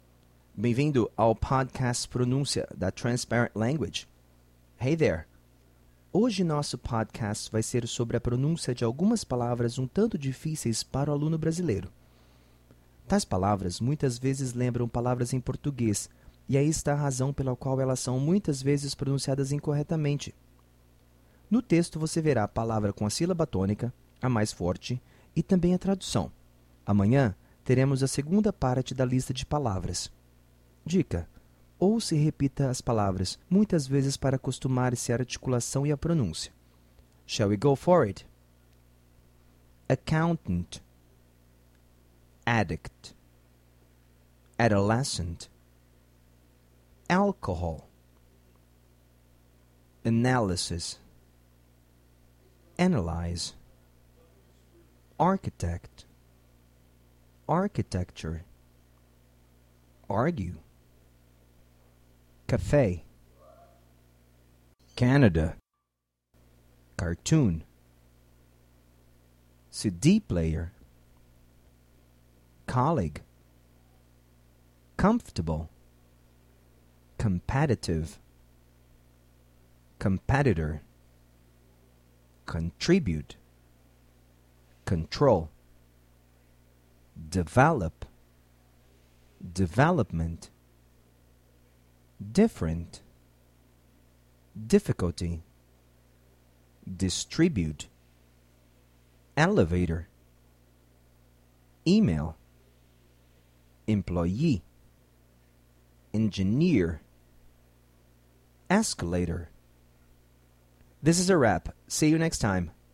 Dica: ouça e repita as palavras muitas vezes para acostumar-se à articulação e pronúncia.
pronunciation-difficult-sounds-01.mp3